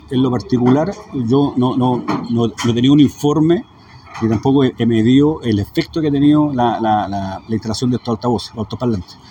También se le consultó al general de Carabineros en la región del Bío Bío, César Bobadilla, sobre si tenía algún informe que avalara la efectividad de estos dispositivos emplazados en el centro de Concepción y dijo que no han recibido reportes desde el municipio.